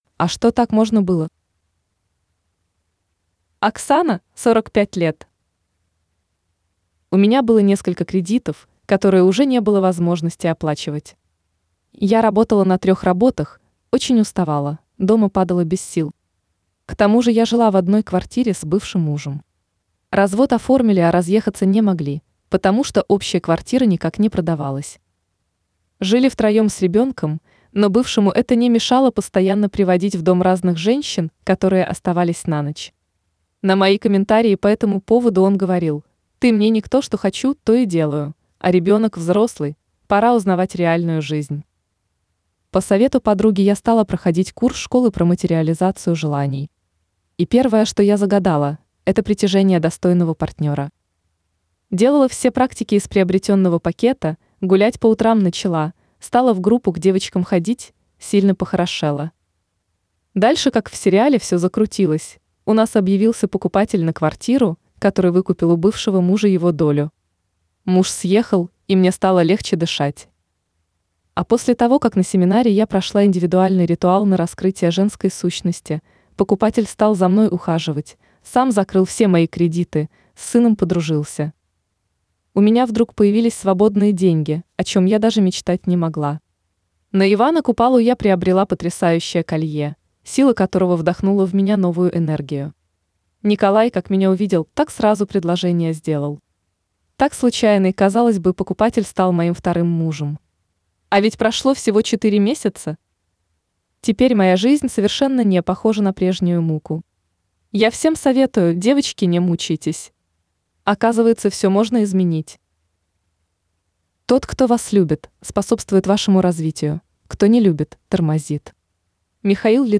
Жанр: Аудио книга.